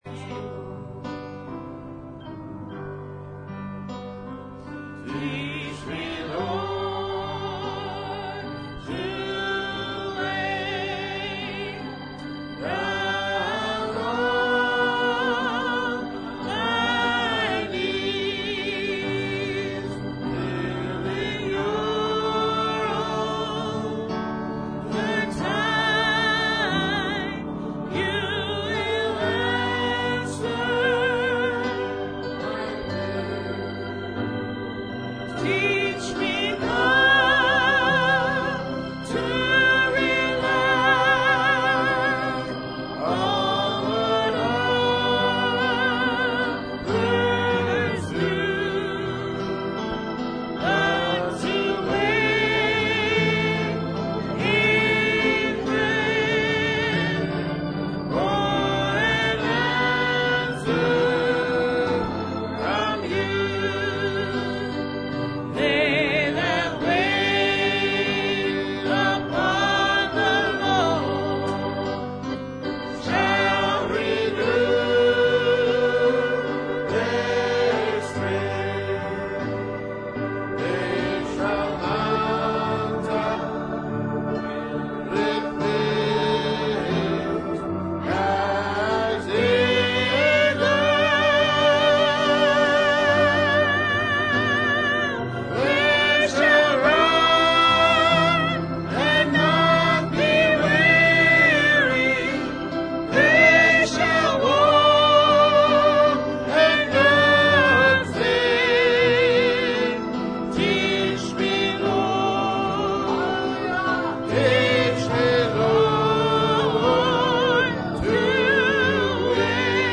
Please REGISTER or LOG-IN to LiveStream or View Archived Sermons.